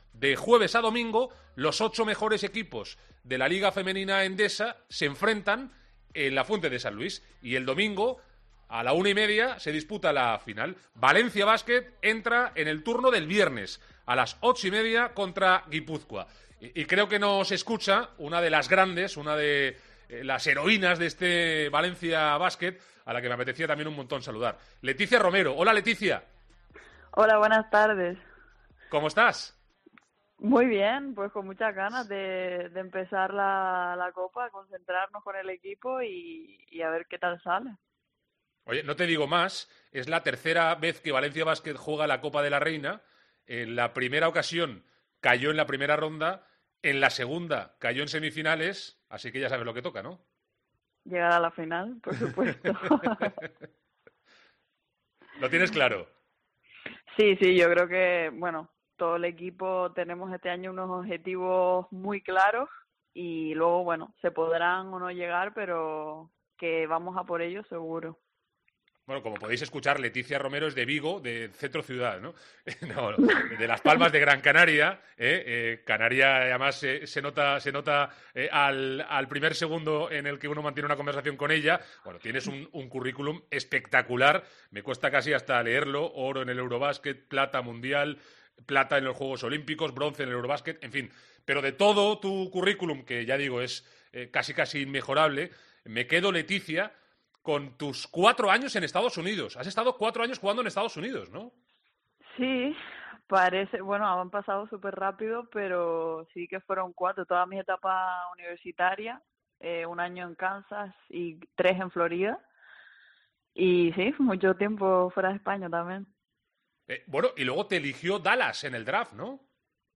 AUDIO. Leticia Romero, jugadora de VBC, en COPE